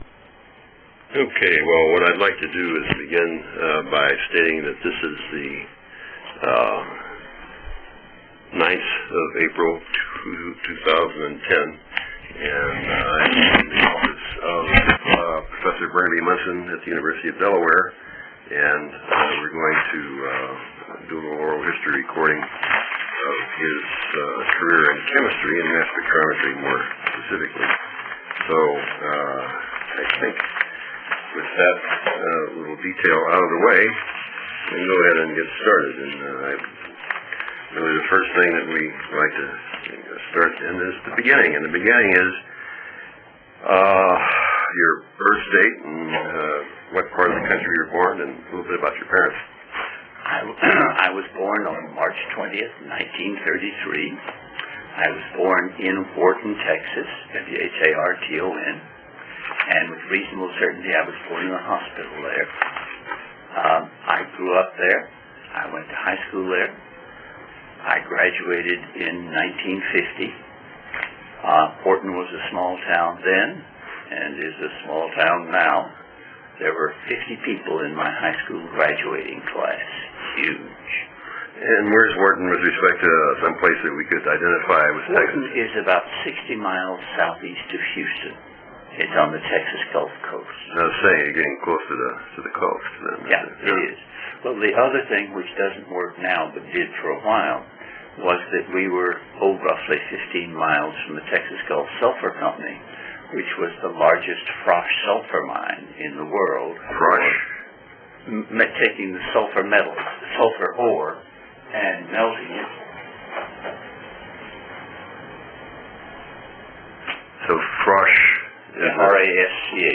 Oral history interview
Place of interview University of Delaware